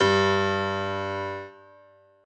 b_basspiano_v127l1o3g.ogg